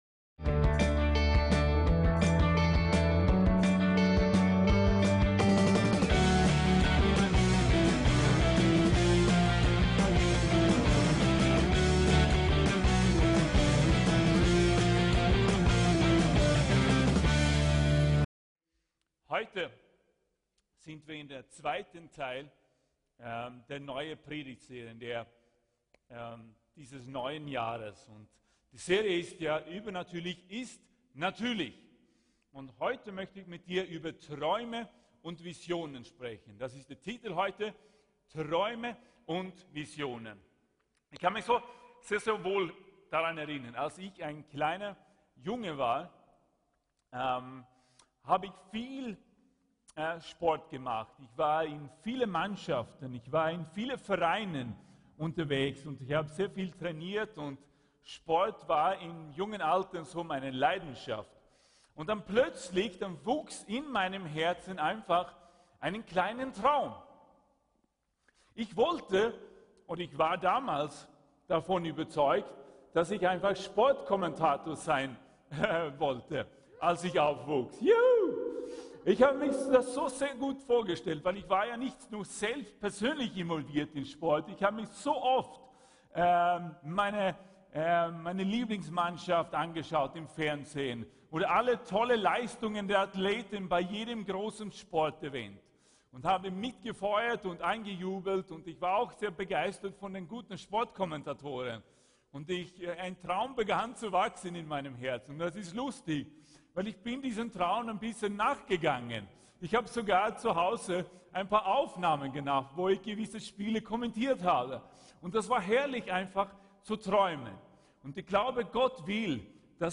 TRÄUME & VISIONEN -Teil 2 " Übernatürlich ist Natürlich " ~ VCC JesusZentrum Gottesdienste (audio) Podcast